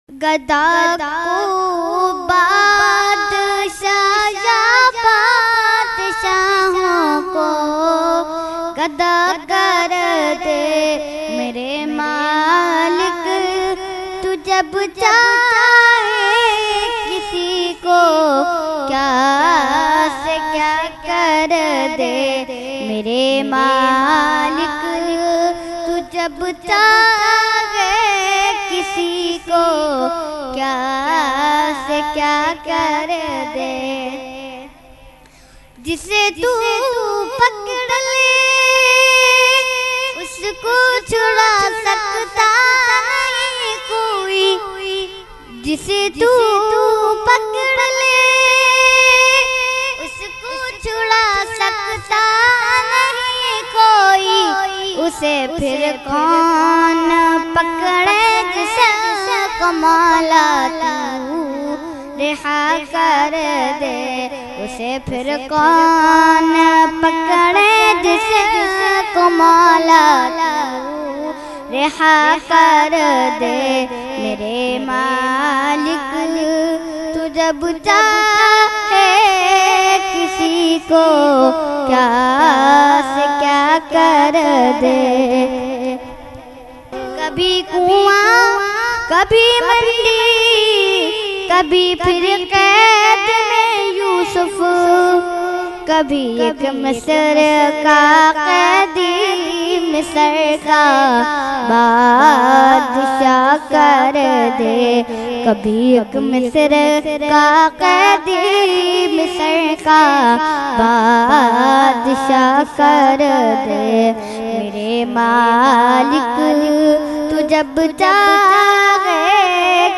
Ikhtitaam e Dua e Hizbul Bahar Mehfil held on 30 April 2022 at Dargah Alia Ashrafia Ashrafabad Firdous Colony Gulbahar Karachi.